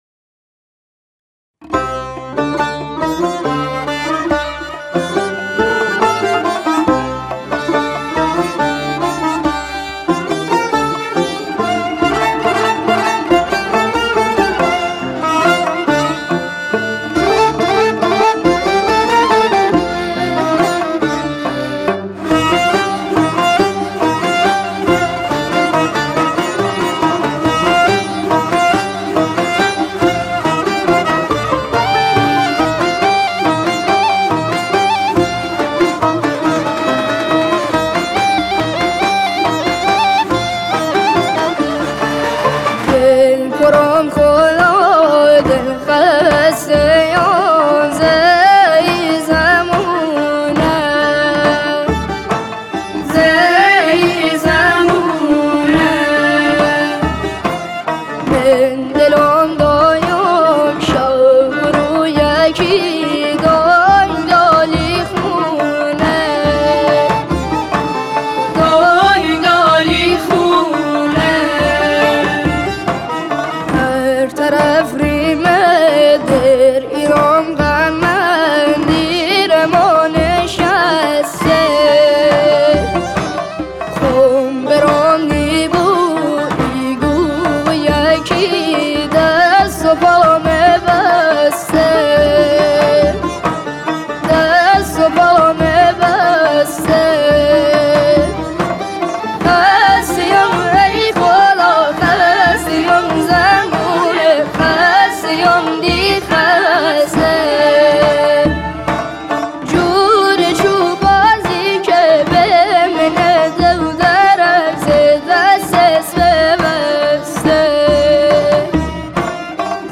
واقعا زیباست هم نت ها هم صدای اواز و سوز ناک
عالی. صدای. بسیار دلنشین و سوزناکی داره.